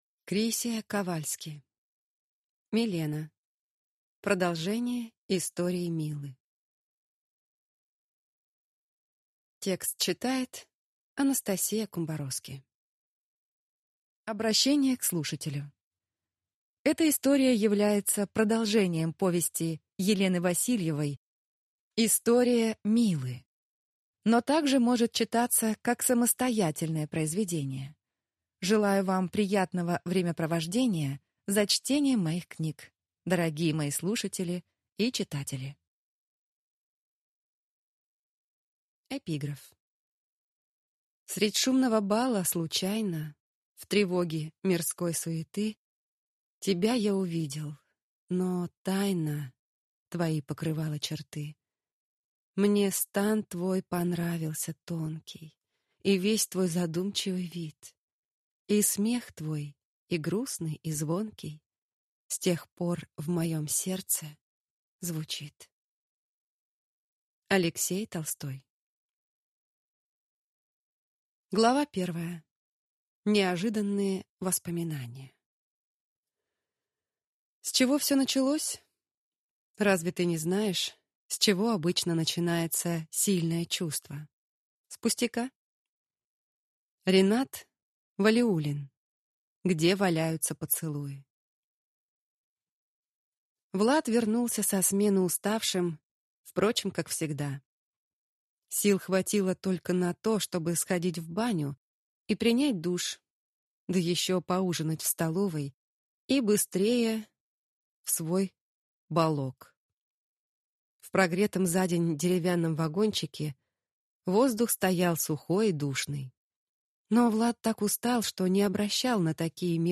Аудиокнига Милена. Продолжение «Истории Милы» | Библиотека аудиокниг